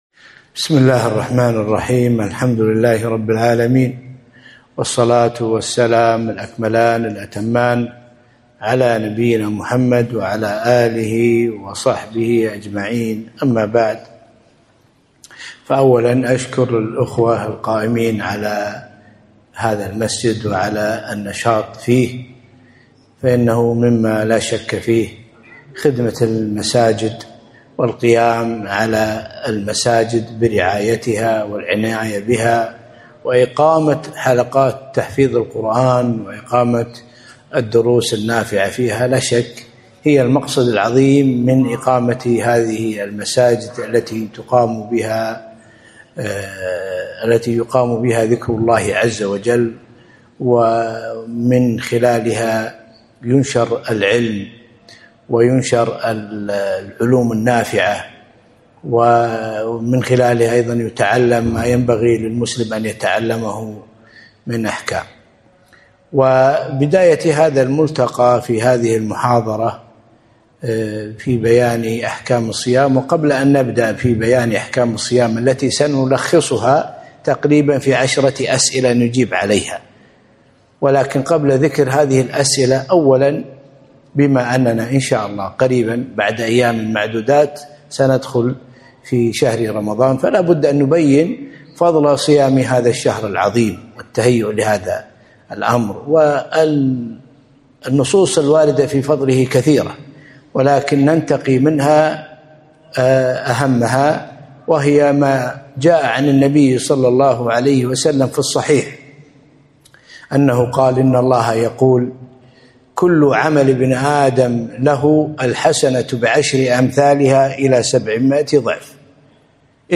محاضرة - أحكام الصيام - دروس الكويت